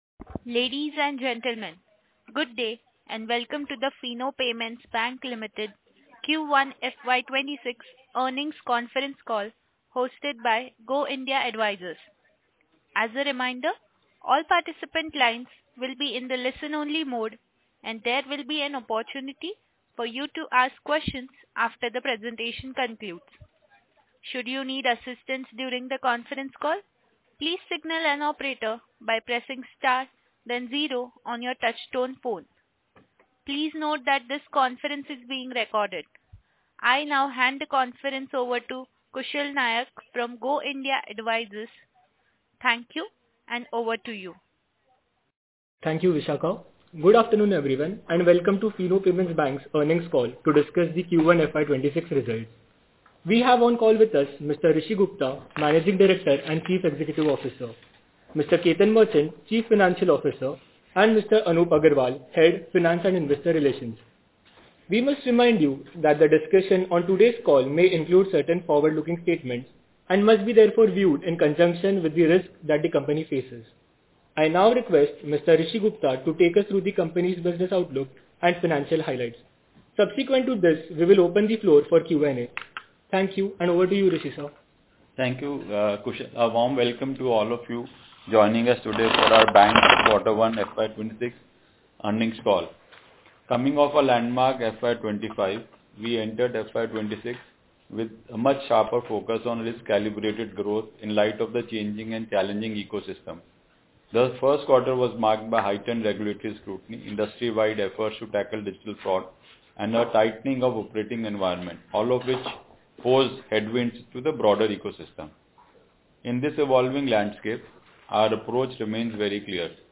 Audio recording of earnings call
Fino-Q1-26-Earnings-Call-Audio.mp3